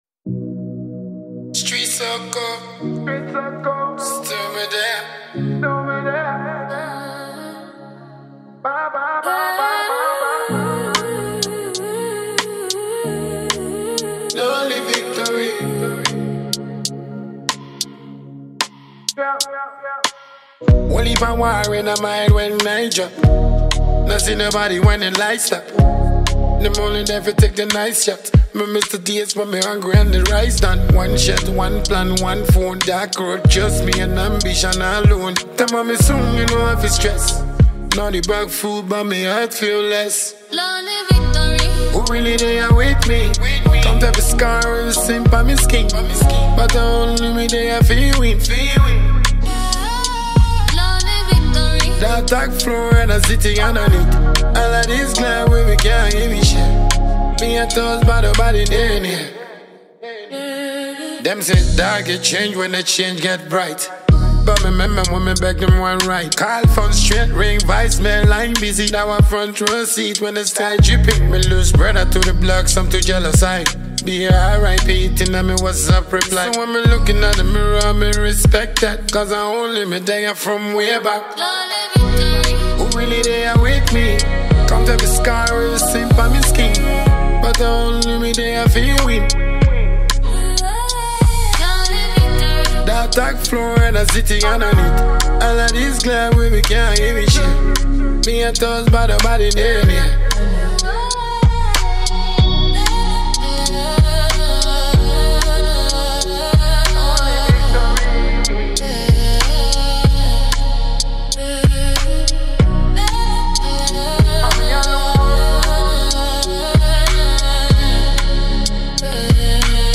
Genre: Dancehall / Afrobeat